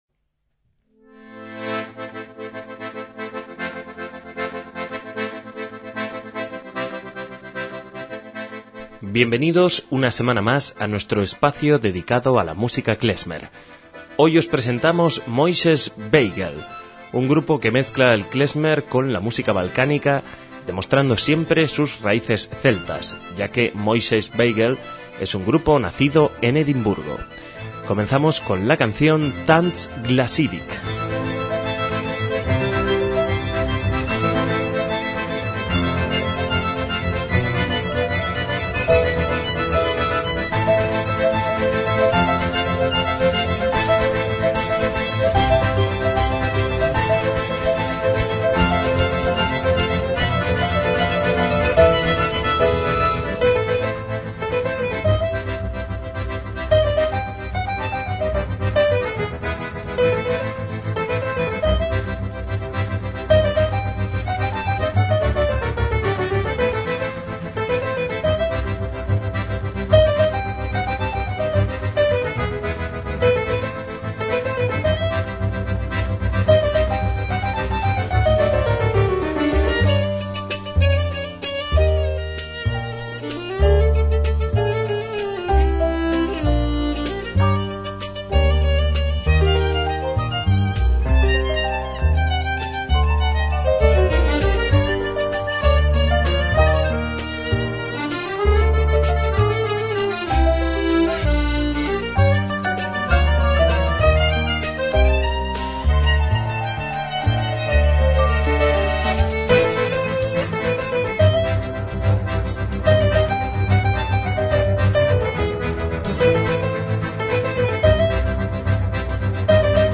MÚSICA KLEZMER
piano y acordeón
violín y mandolina
bajo y cavaquinho
percusión